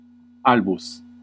Ääntäminen
IPA : /waɪt/ US